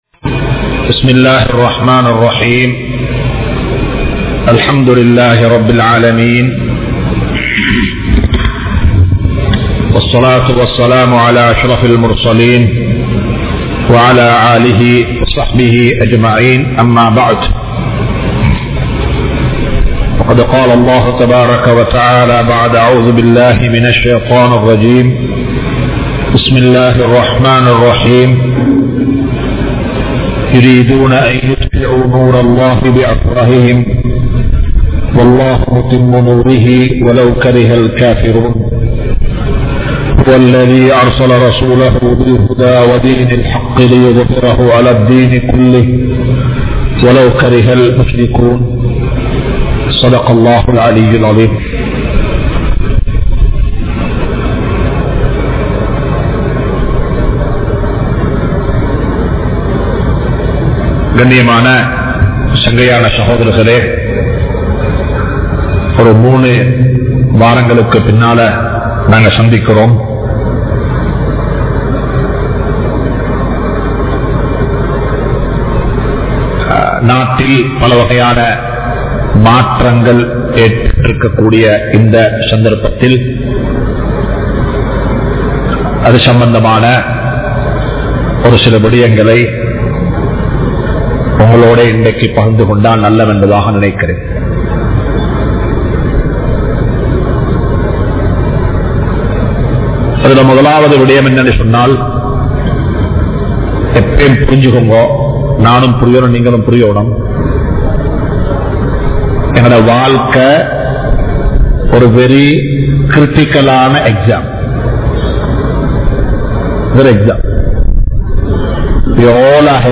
Ithu Niranthara veattri Illai (இது நிரந்தர வெற்றி இல்லை) | Audio Bayans | All Ceylon Muslim Youth Community | Addalaichenai
Majma Ul Khairah Jumua Masjith (Nimal Road)